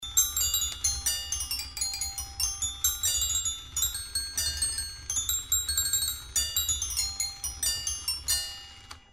carillon 17mo secolo
Esempio audio: non identificato Carillon, Augsburg, 17mo secolo
carillon.mp3